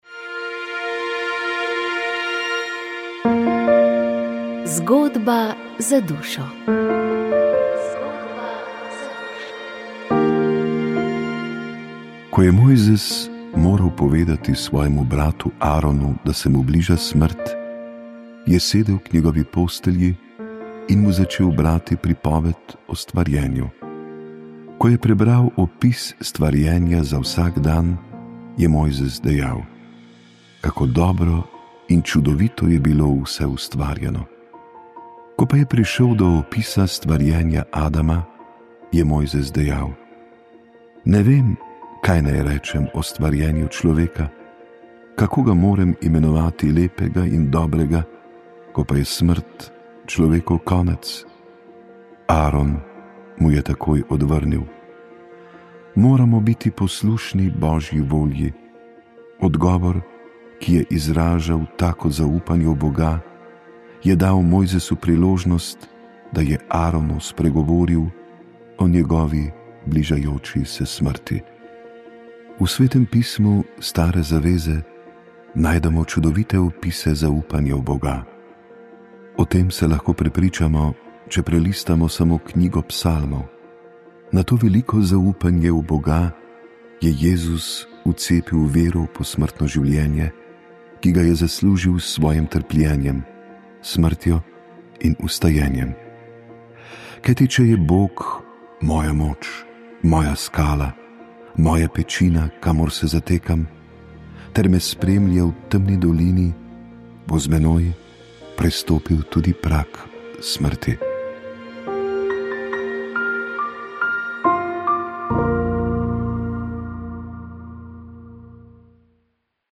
je odgovarjal na vprašanja poslušalcev.